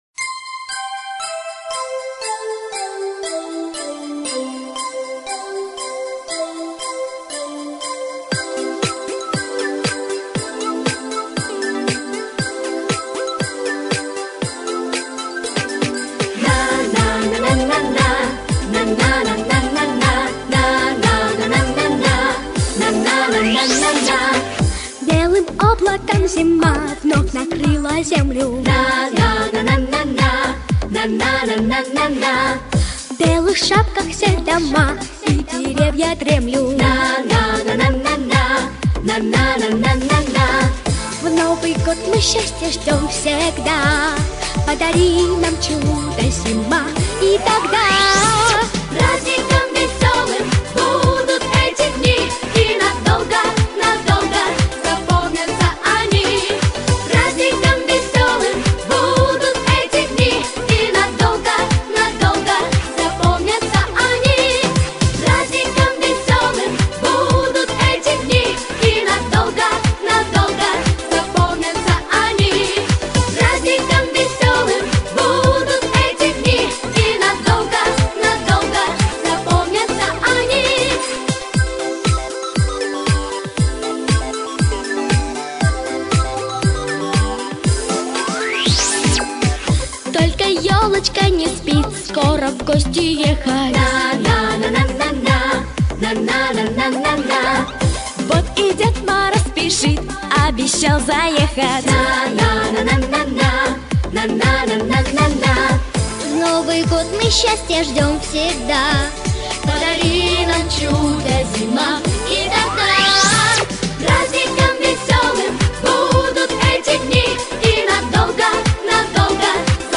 • Категория: Детские песни
Скачать плюс